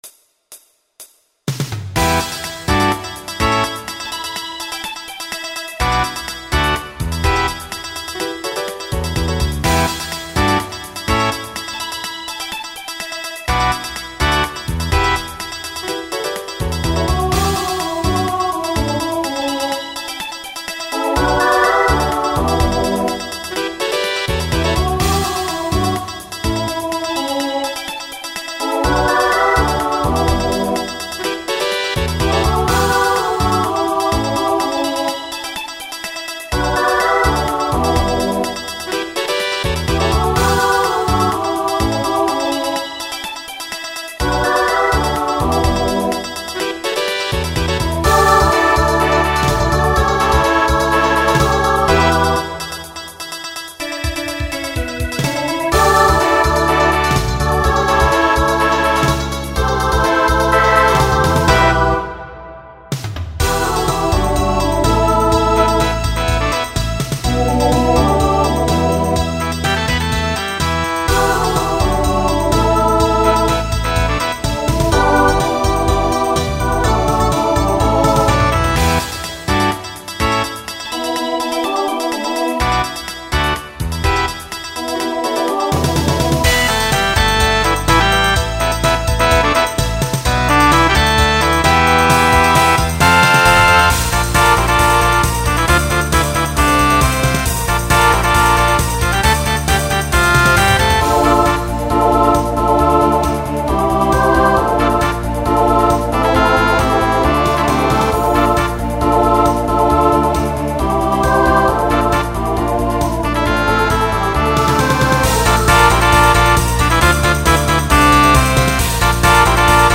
Pop/Dance , Rock
Transition Voicing SATB